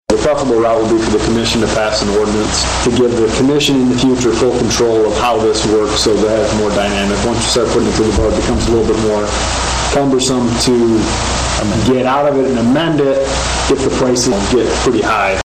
Commissioner Marvin Smith made a motion to let the contract end and present a referendum to the citizens to vote on. City Attorney TJ Reed says that’s not a good way to go for Sturgis.